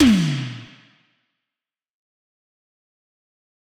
Tom_G1.wav